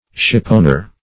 Search Result for " shipowner" : Wordnet 3.0 NOUN (1) 1. someone who owns a ship or a share in a ship ; The Collaborative International Dictionary of English v.0.48: Shipowner \Ship"own`er\, n. Owner of a ship or ships.